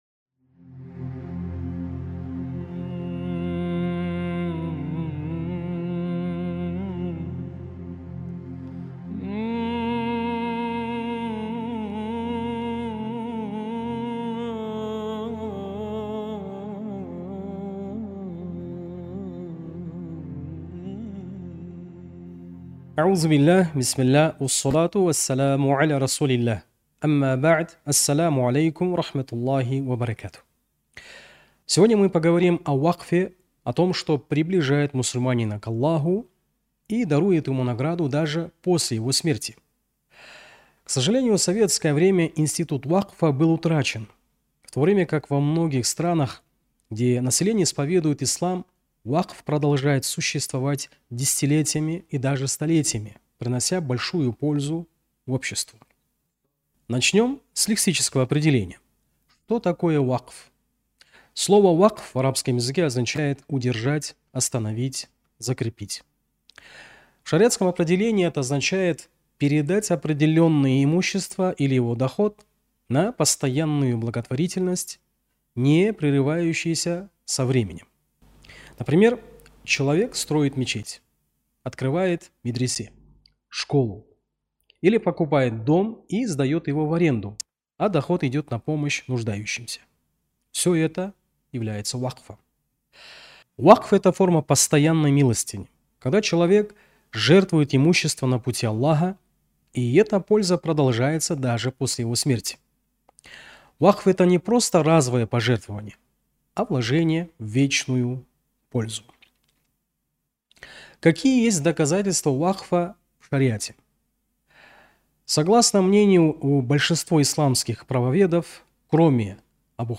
В лекциях этого цикла мы будем пояснять проповедь, которая проходит в эту пятницу в мечетях города.